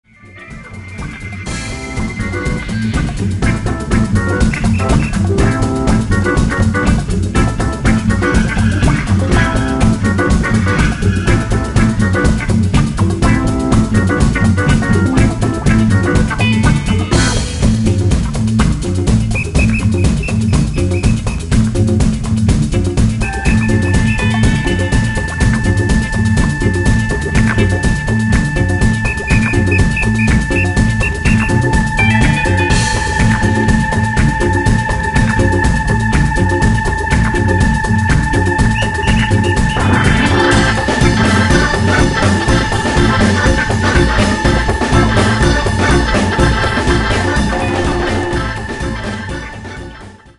Hammond organ
guitar & effects
drums
percussion
is a nine-minute, Afro-Cuban, freak-out jam.